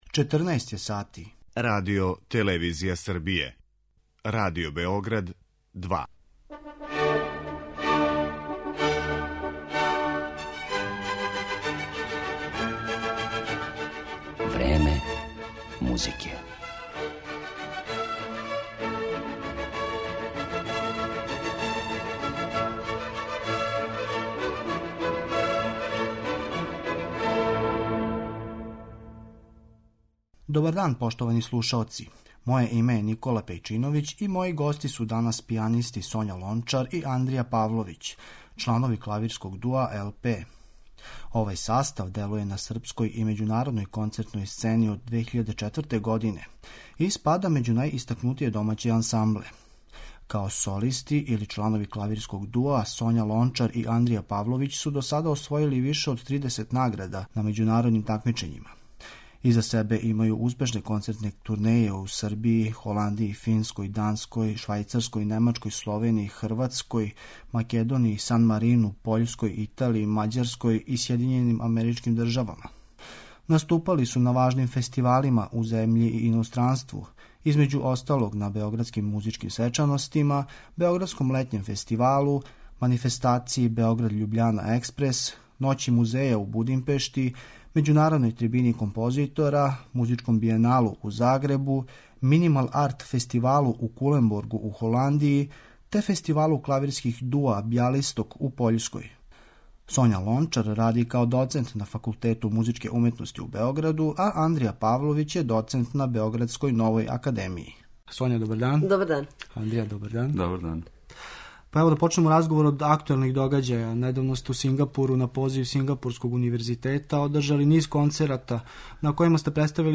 Клавирски дуо LP
Гости у данашњој емисији су пијанисти